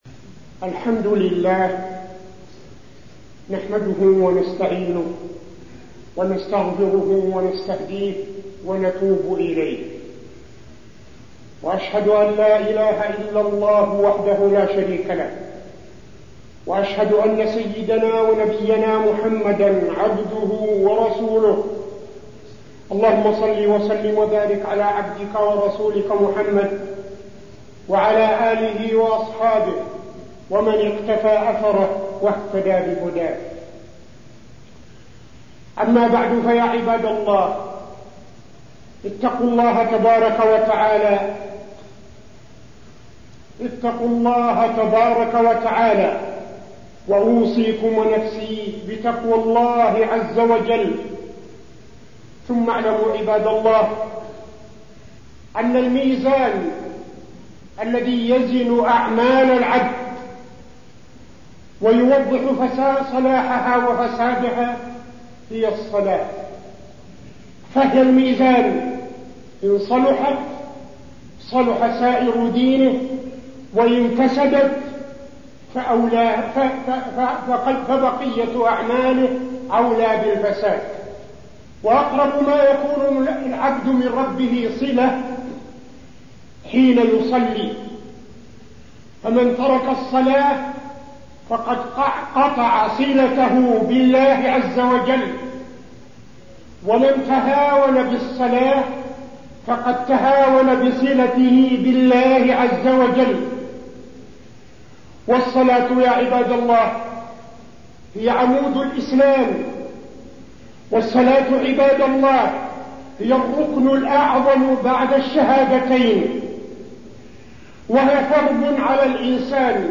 تاريخ النشر ٢٧ محرم ١٤٠٦ هـ المكان: المسجد النبوي الشيخ: فضيلة الشيخ عبدالعزيز بن صالح فضيلة الشيخ عبدالعزيز بن صالح الصلاة